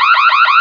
_ALARM2.mp3